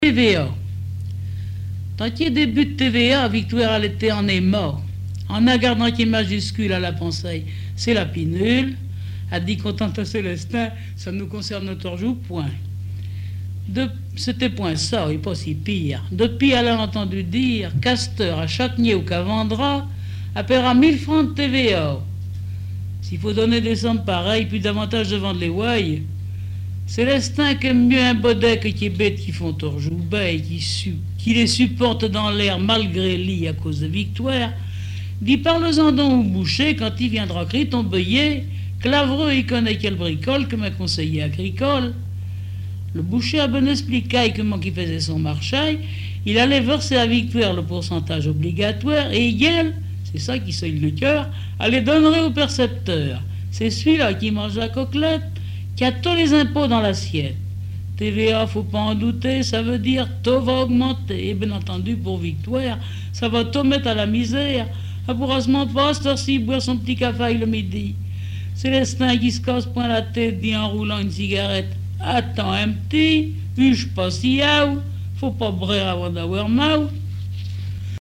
Langue Patois local
Genre récit